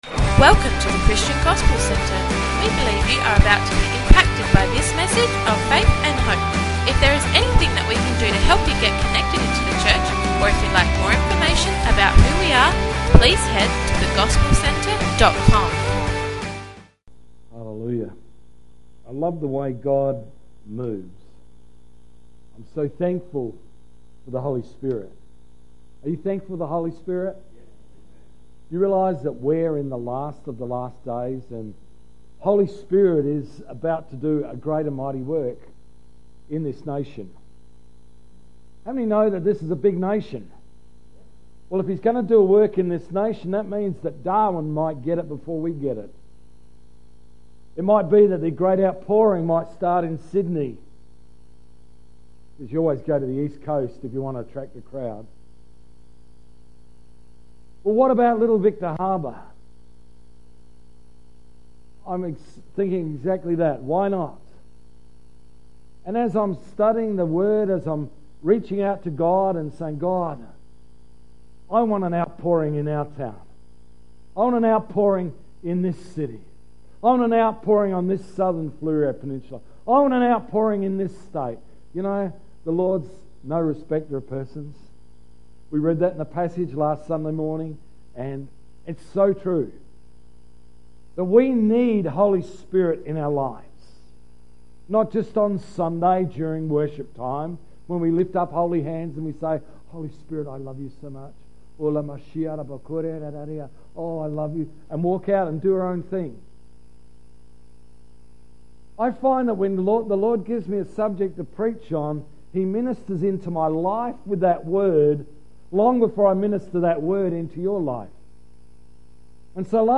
28th February 2016 – Morning Service